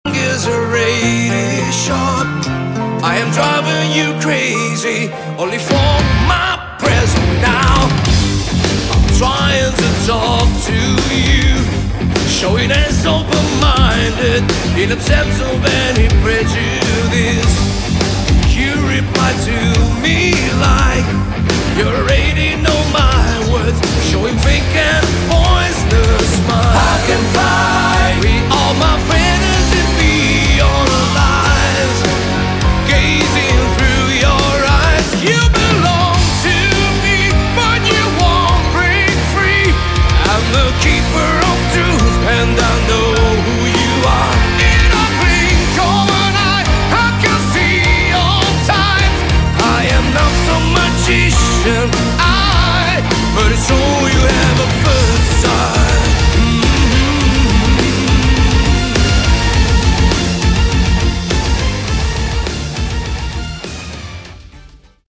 Category: Prog Rock
piano, keyboards
guitars
drums